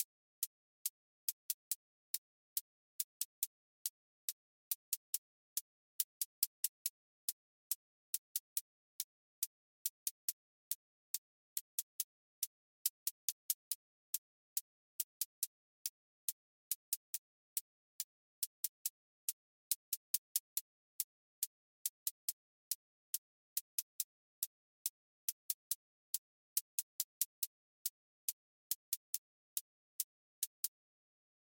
Trap 808 tension with clipped hats